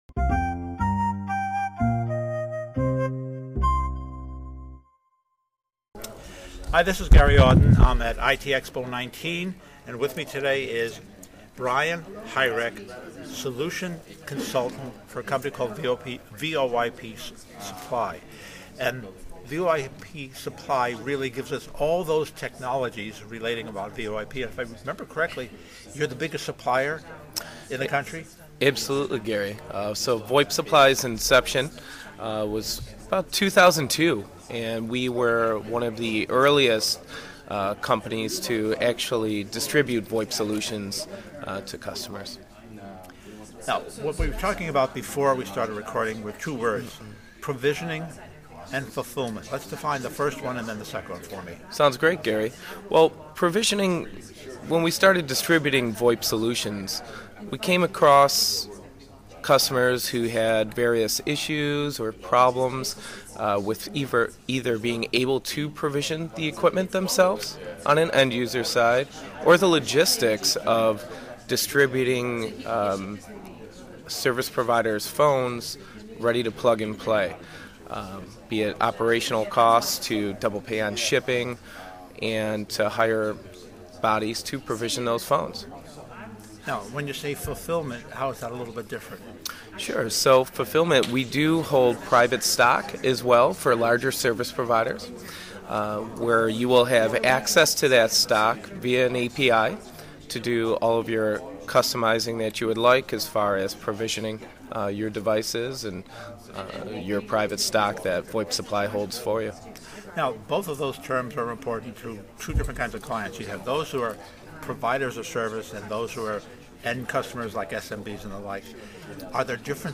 VoIP Supply on Telecom Reseller Podcast at ITEXPO 2019!
discussed at ITEXPO 2019